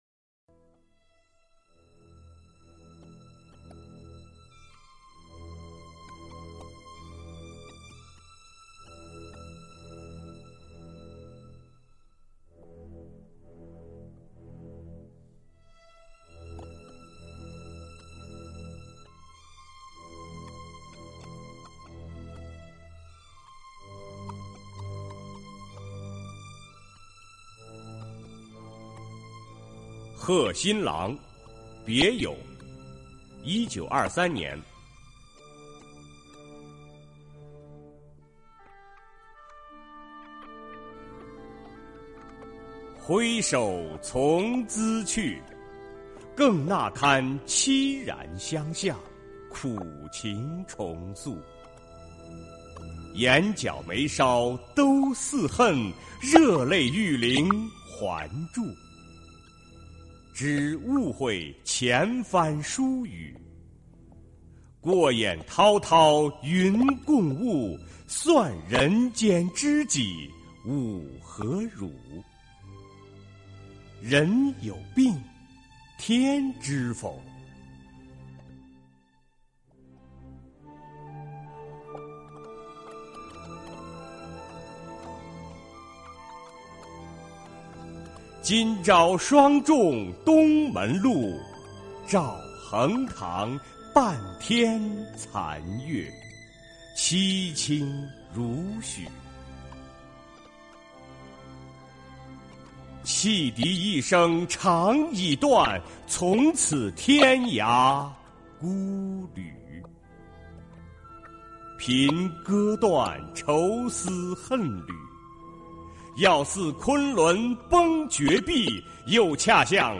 首页 视听 经典朗诵欣赏 毛泽东：崇高优美、超越奇美、豪华精美、风格绝殊